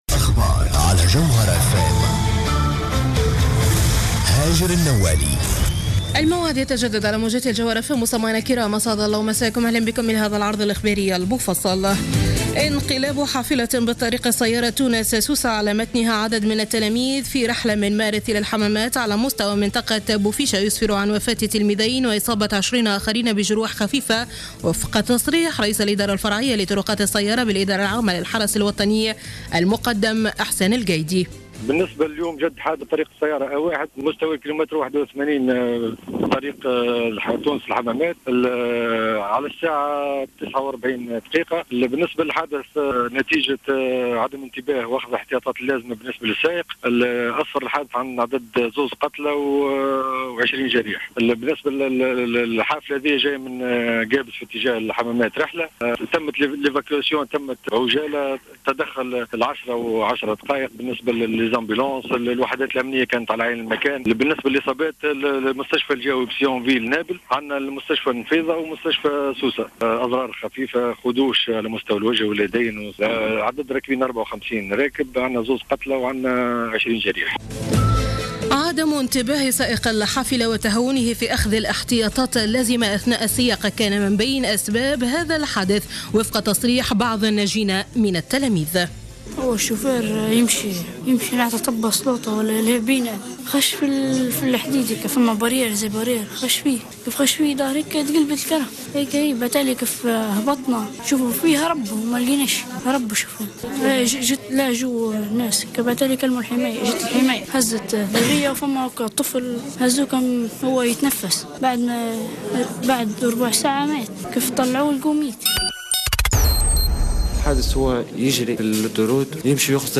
نشرة أخبار السابعة مساء ليوم الثلاثاء 24-03-15